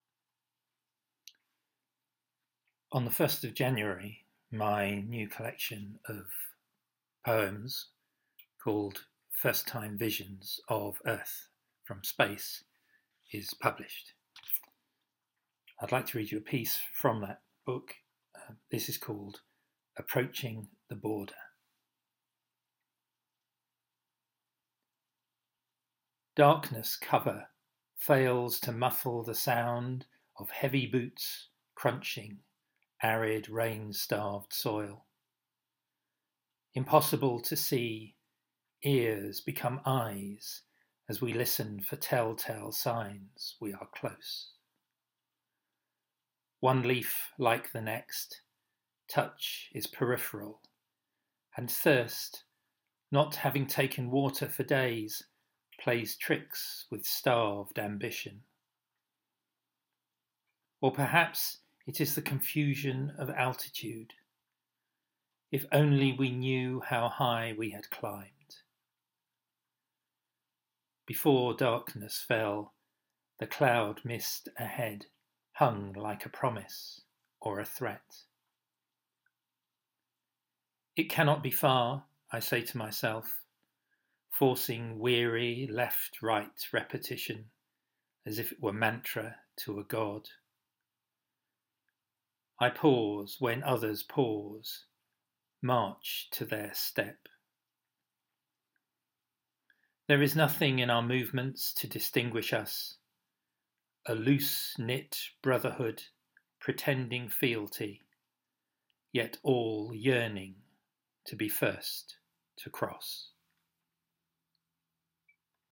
A second reading from my new volume of poetry.